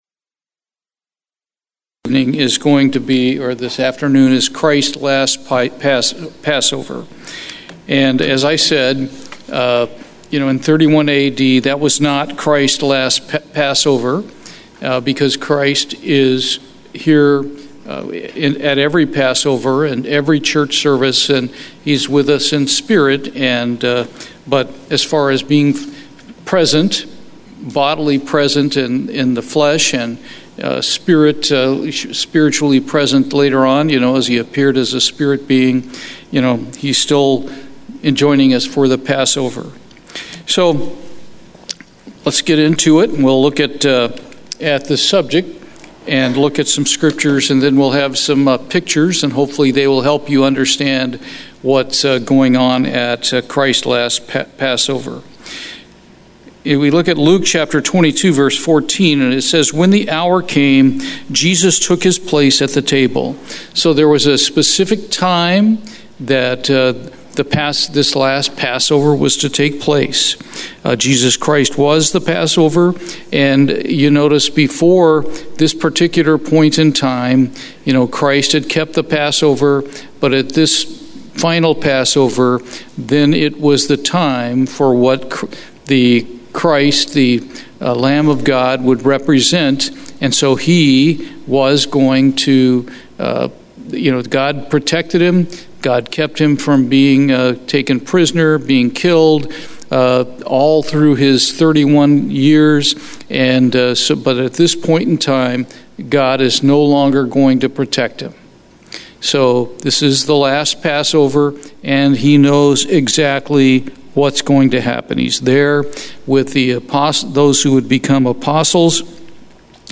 Bible Study, Christ's Last Passover